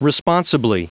Prononciation du mot responsibly en anglais (fichier audio)
Prononciation du mot : responsibly